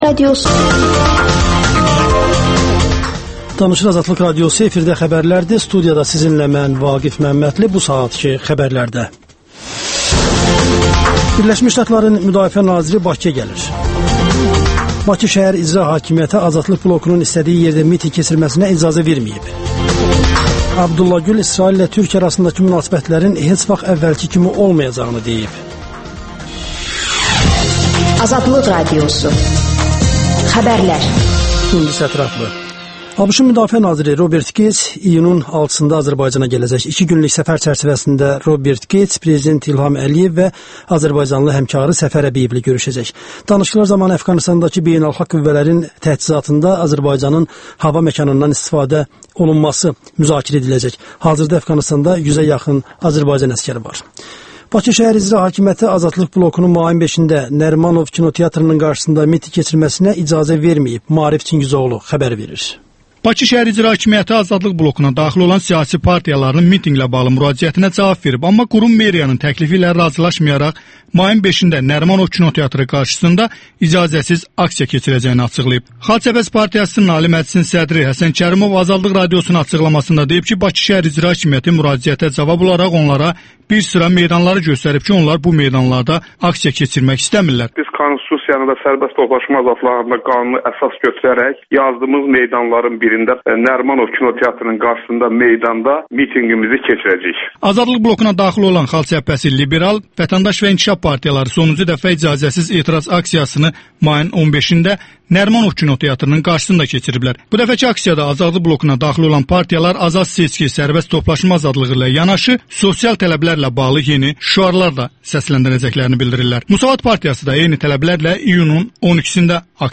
Siz də canlı efirdə hüquqi məsləhət ala bilərsiz.